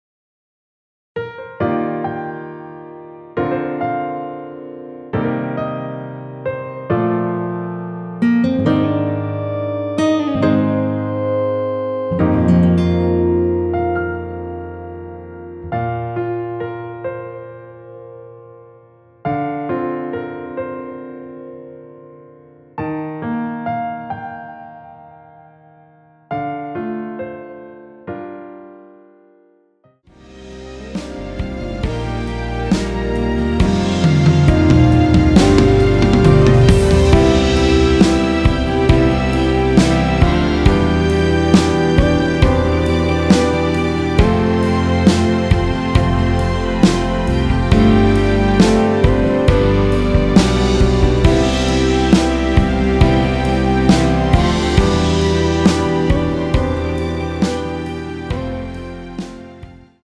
원키에서(-2)내린 MR입니다.
Bb
앞부분30초, 뒷부분30초씩 편집해서 올려 드리고 있습니다.